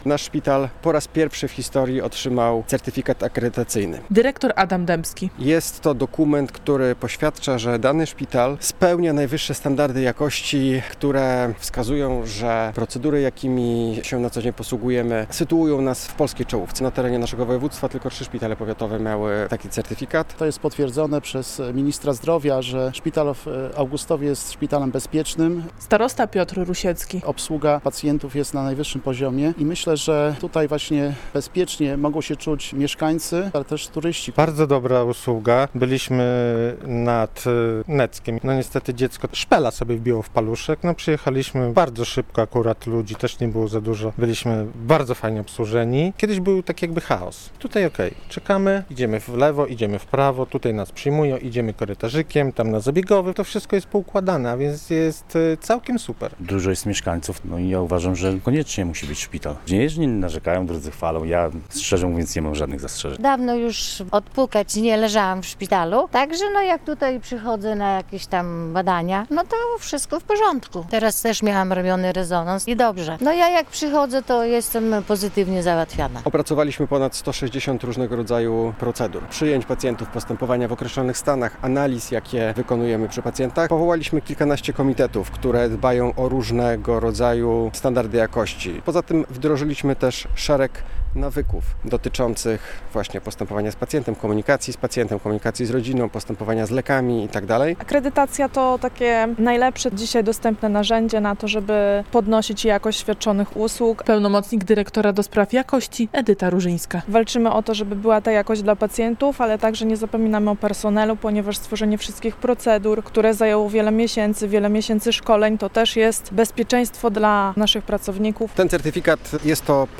Augustowski szpital pierwszy raz w historii z certyfikatem akredytacyjnym - relacja